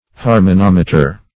Search Result for " harmonometer" : The Collaborative International Dictionary of English v.0.48: Harmonometer \Har`mo*nom"e*ter\ (-n[o^]m"[-e]*t[~e]r), n. [Gr.